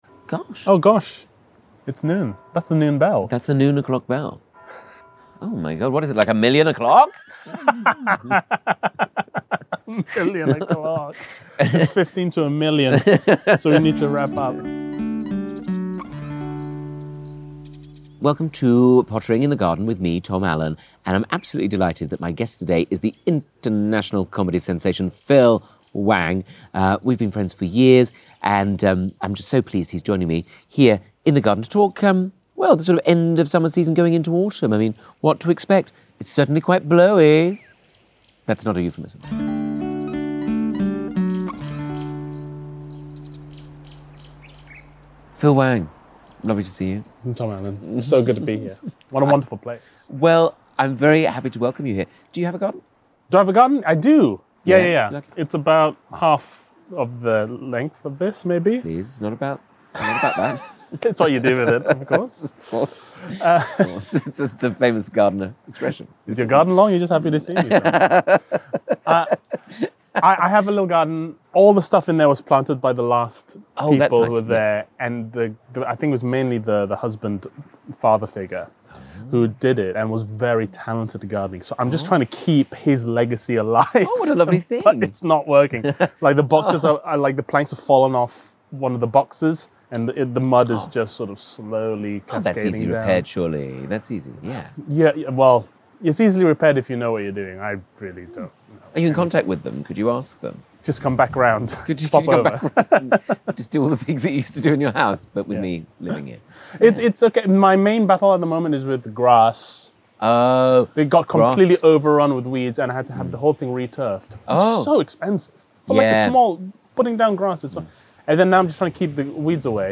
Another week, another brilliant guest joining me in the garden, the fabulous Phil Wang!